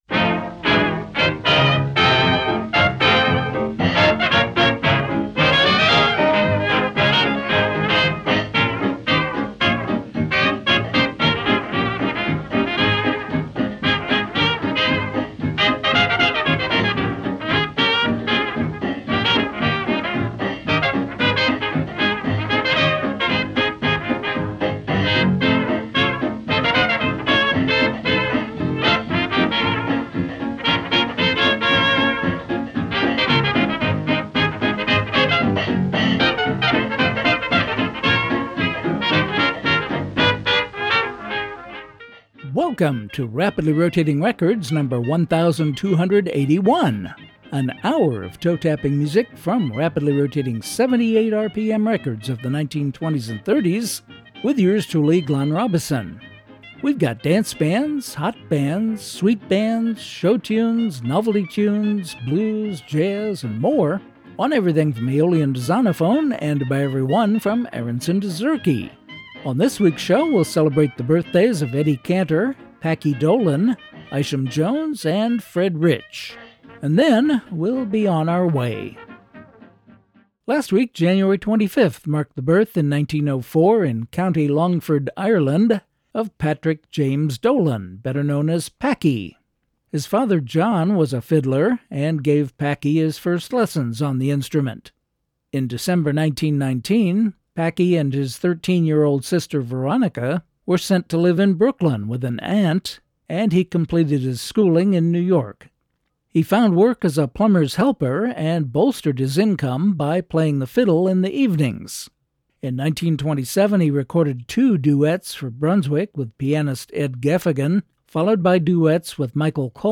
There’s lots of great music and interesting information so set aside an hour with your favorite beverage and prepare to be transported back to a different–and we think better–musical era.